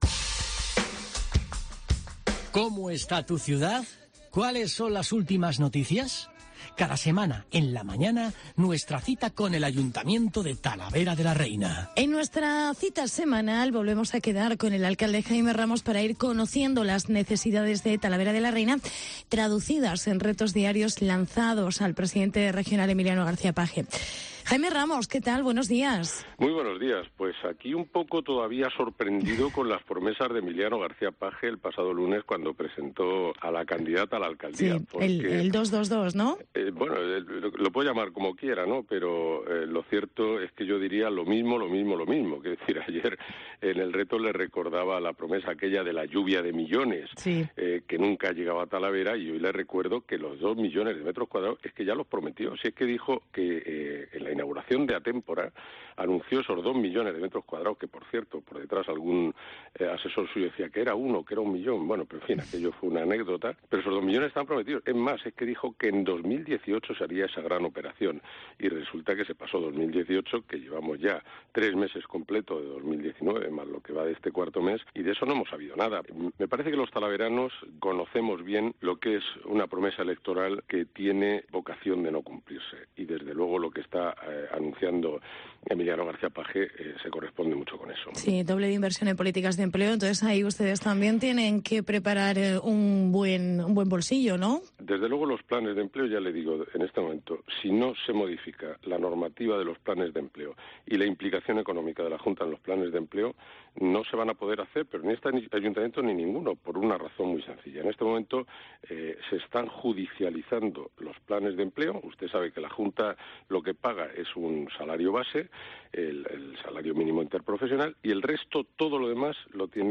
Declaraciones de Jaime Ramos. Alcalde de Talavera